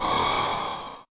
breath.ogg